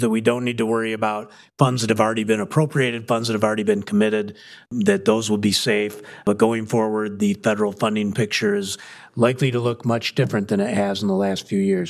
Portage City Manager Pat McGinnis told his council this week that several local leaders recently got a chance to meet with Michigan’s new Democratic Senator, Elissa Slotkin, and she had mixed news.